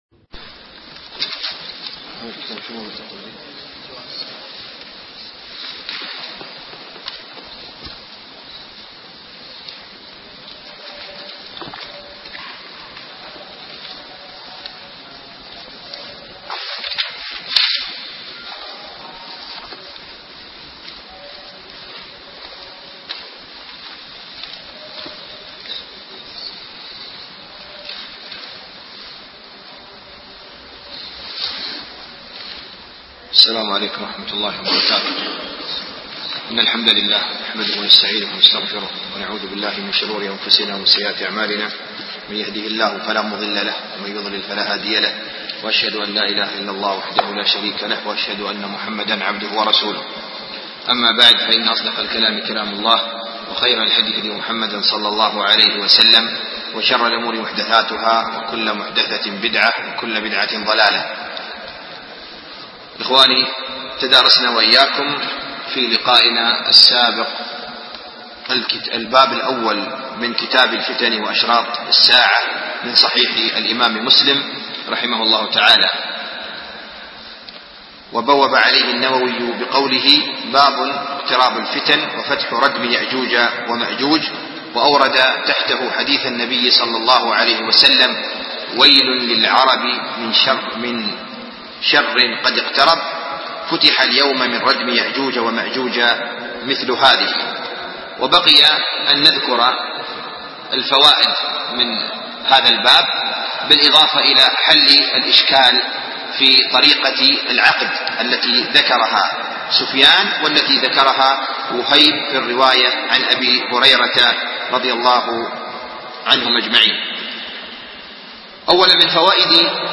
شرح كتاب الفتن من صحيح مسلم - الدرس الثاني